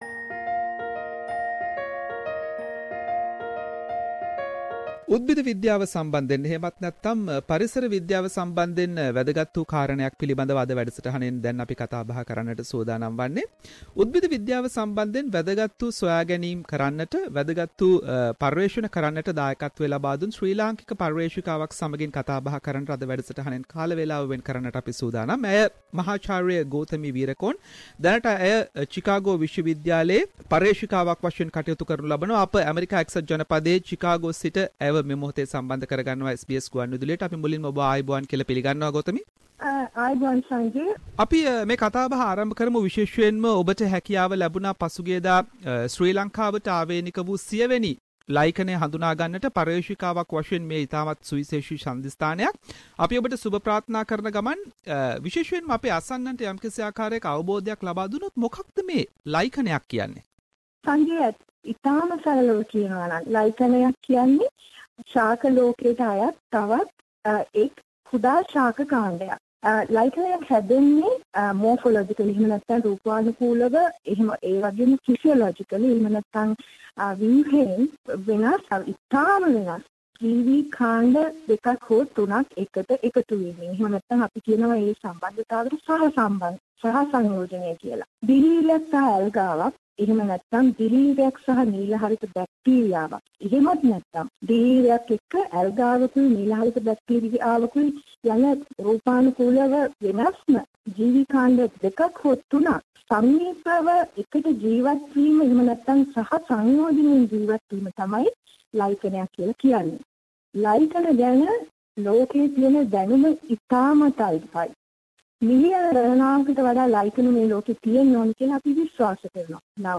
SBS Sinhalese program interviewed